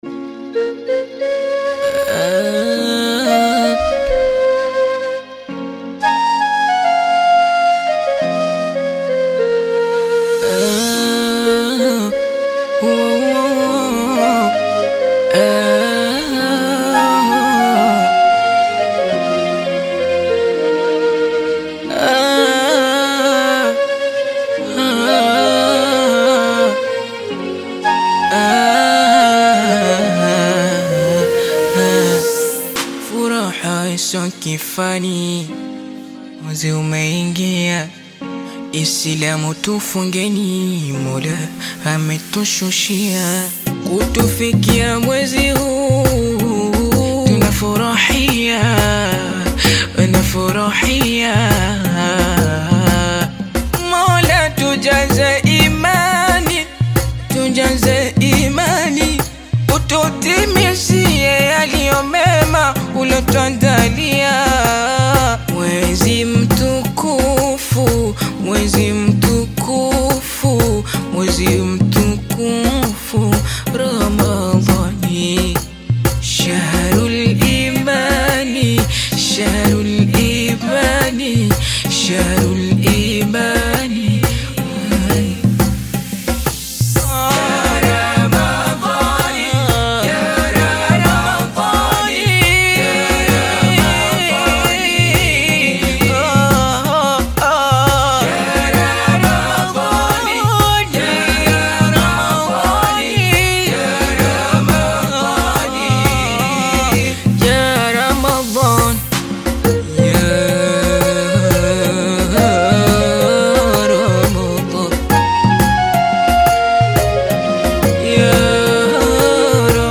KASWIDA